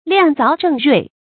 量鑿正枘 注音： ㄌㄧㄤˋ ㄗㄠˊ ㄓㄥˋ ㄖㄨㄟˋ 讀音讀法： 意思解釋： 謂木工度量器物孔眼的大小方圓制作可與之相契合的榫頭。